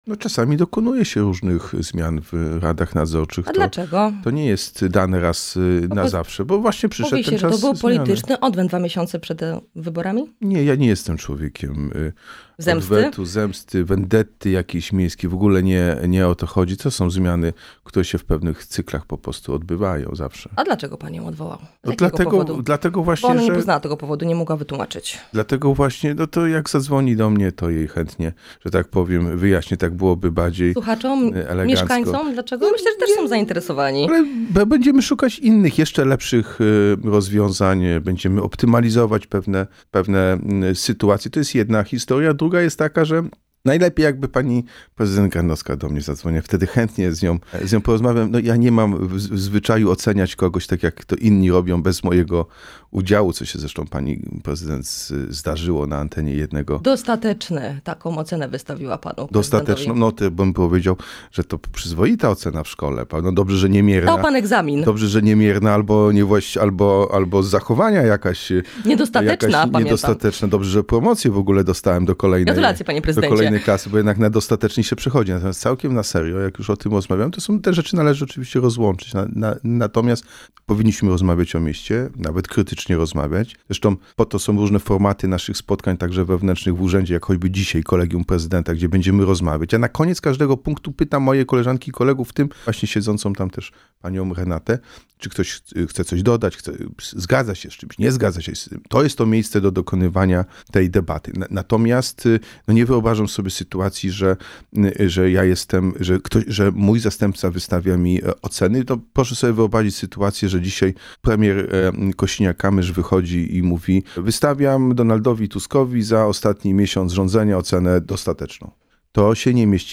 Każdy, kto będzie chciał to porozumienie rozbijać, rozszarpywać, może być winny złego wyniku w wyborach. – mówił w „Porannym Gościu” Jacek Sutryk – prezydent Wrocławia.